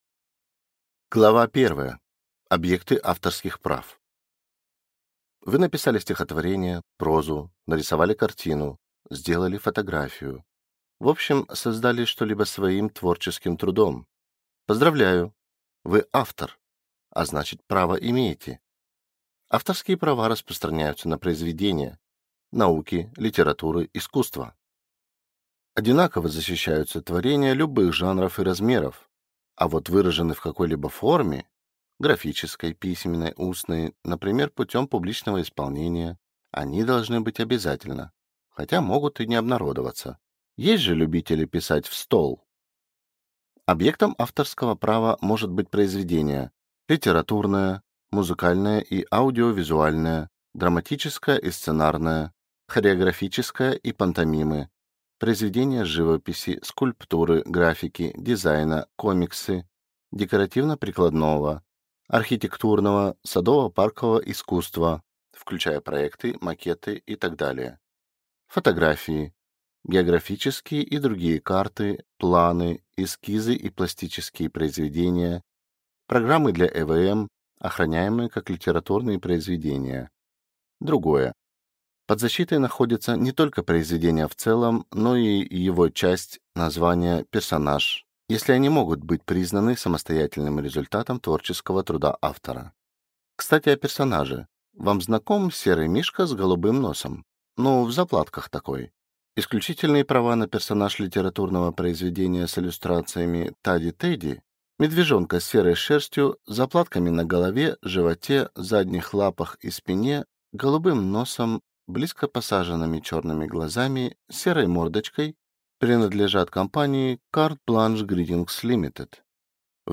Аудиокнига Автор под защитой | Библиотека аудиокниг